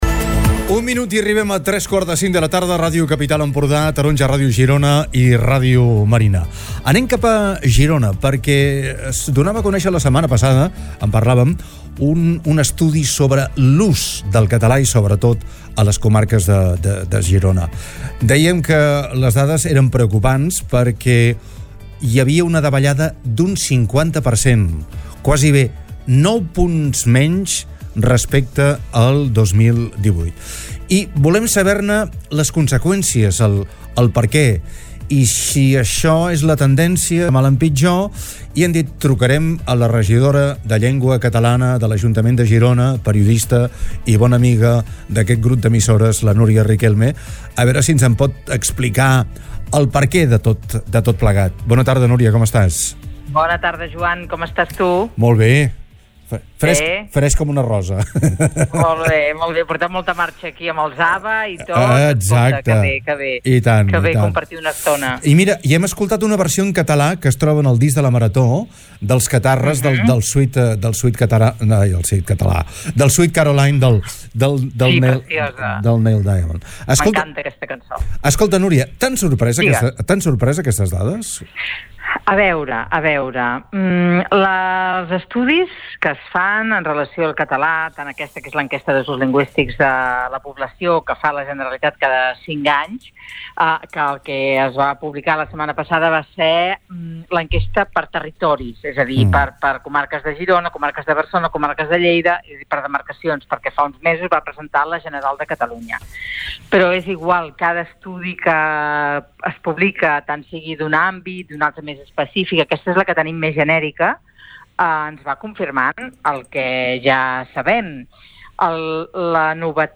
Nuria Riquelme, regidora de llengua catalana de l’ajuntament de Girona ha estat entrevistada